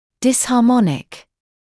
[dɪshɑː’mɔnɪk][дисхаː’моник]дисгармоничный; негармоничный